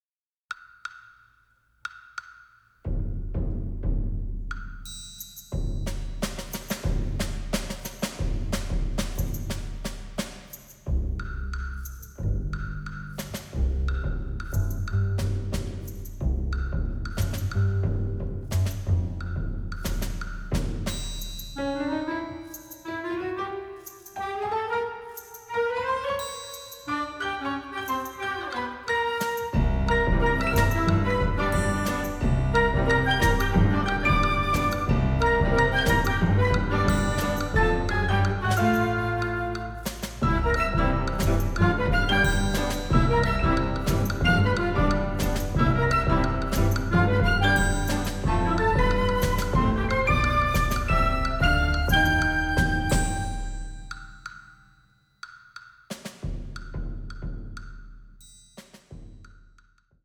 mirroring reality with melancholic orchestration.